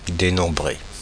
Ääntäminen
Ääntäminen Paris: IPA: [de.nɔ̃.bʁe] France (Paris): IPA: /de.nɔ̃.bʁe/ Haettu sana löytyi näillä lähdekielillä: ranska Käännöksiä ei löytynyt valitulle kohdekielelle.